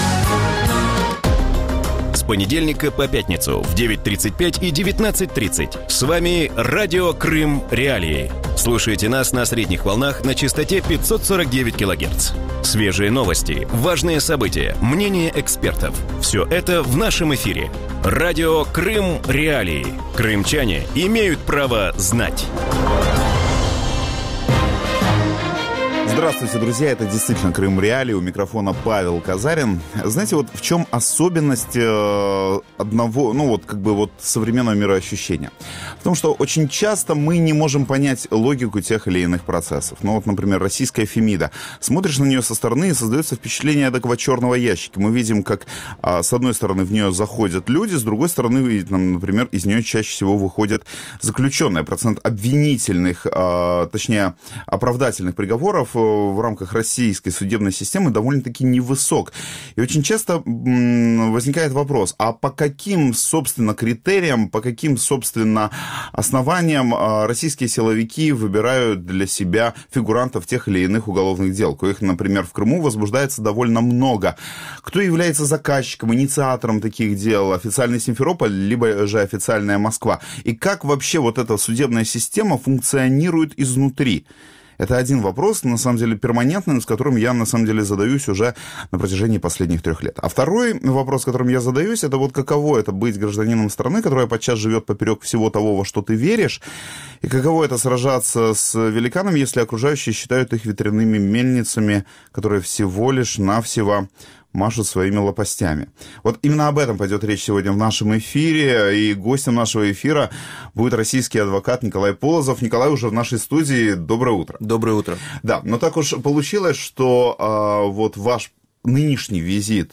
Утром в эфире Радио Крым.Реалии говорят о делах российских адвокатов в Крыму.